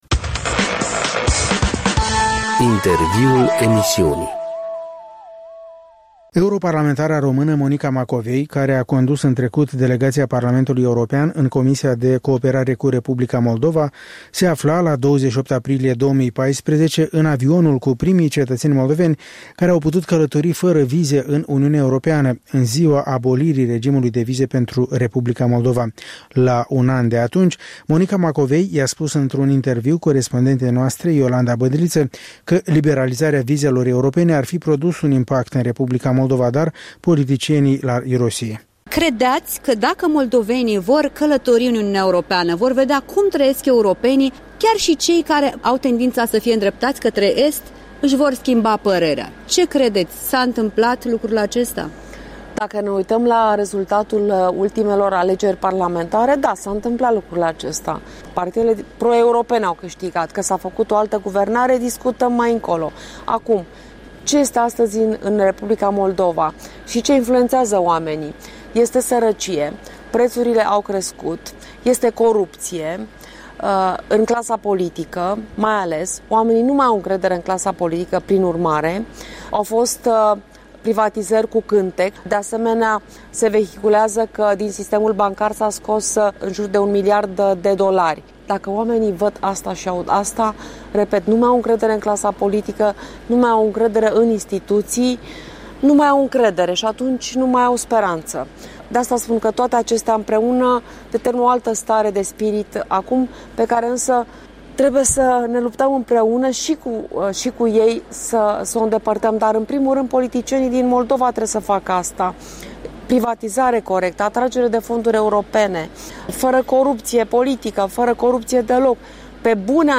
În direct de la Strasbourg cu eurodeputata Monica Macovei